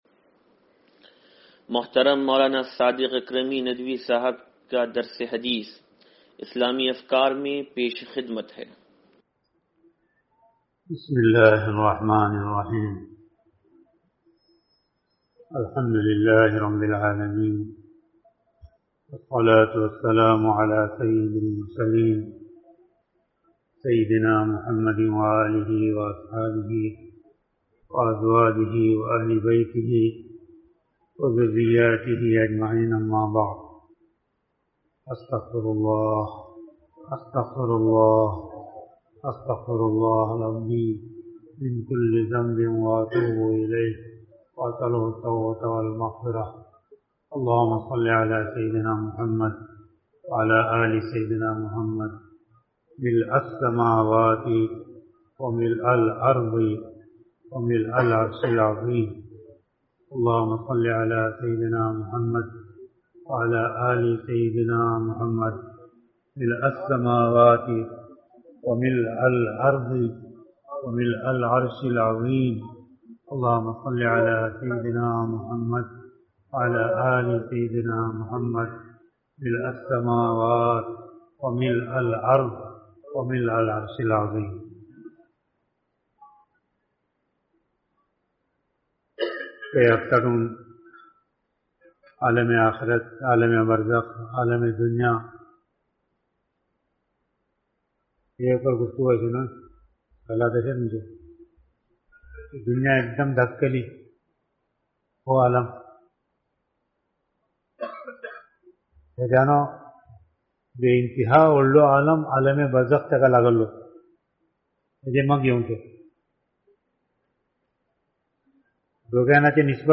درس حدیث نمبر 0598